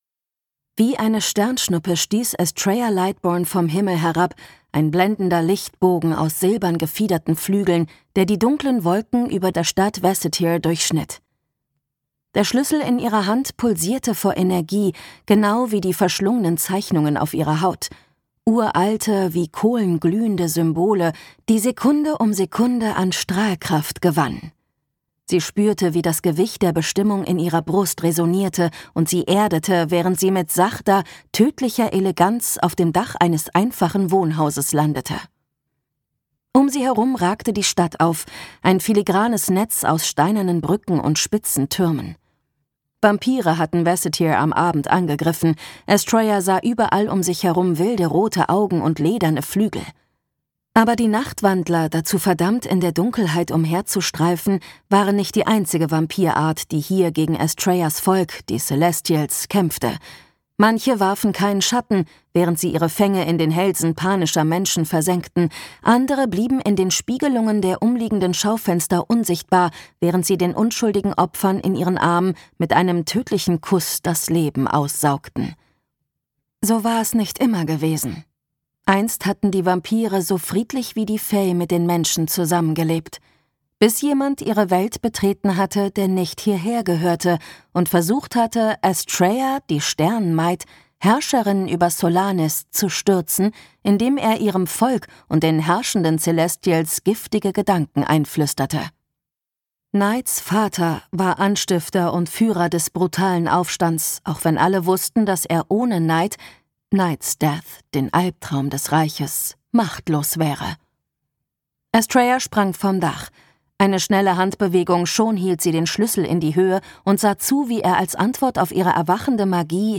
The Dark Is Descending - Chloe C. Peñaranda | argon hörbuch
Gekürzt Autorisierte, d.h. von Autor:innen und / oder Verlagen freigegebene, bearbeitete Fassung.